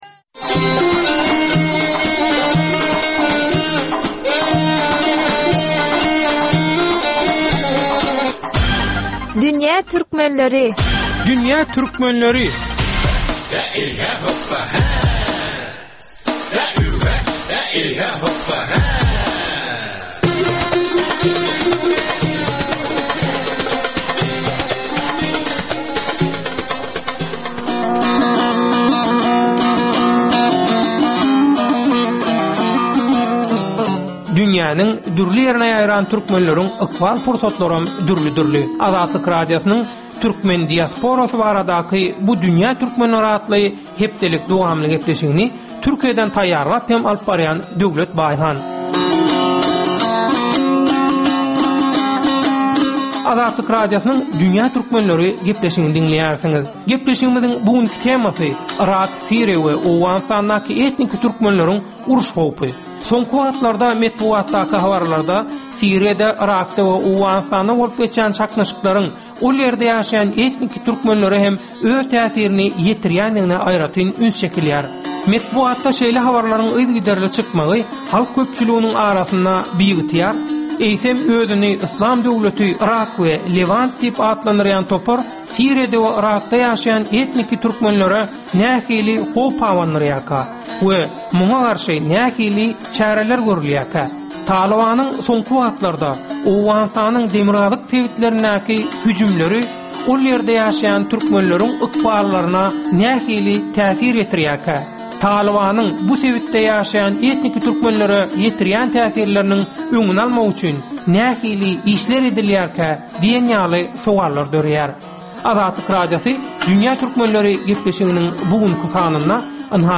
Azatlyk Radiosynyň dünýä türkmenleriniň durmuşyndaky möhum wakalara bagyşlanan ýörite gepleşigi.